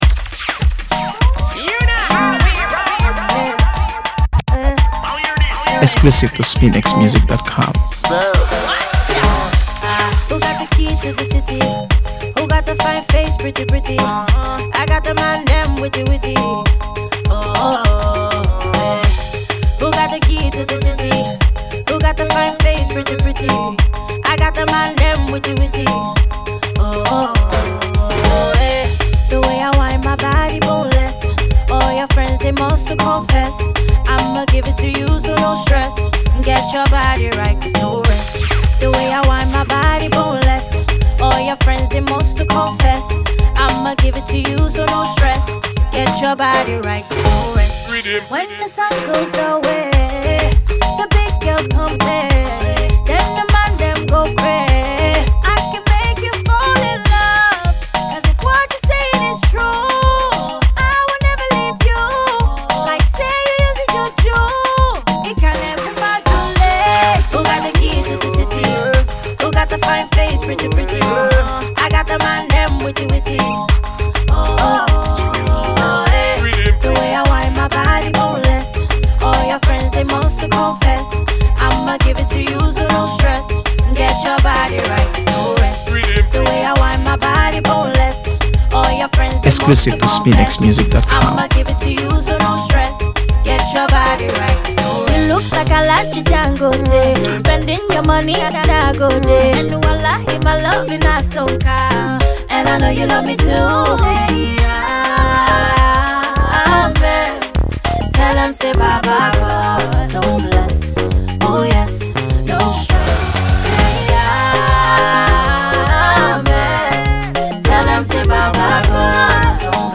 AfroBeats | AfroBeats songs
Fusion of Afro Pop and Dancehall